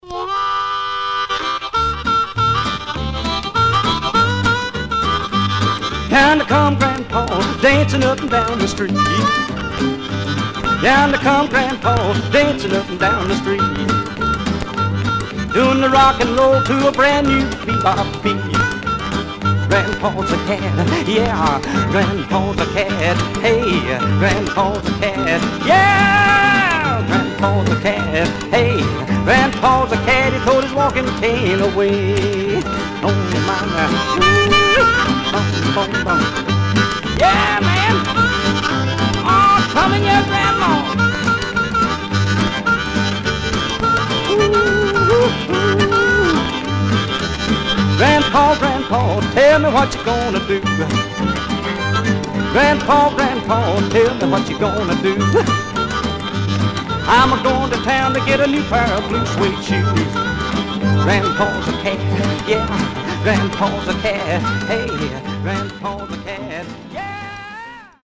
Some surface noise/wear
Mono
Rockabilly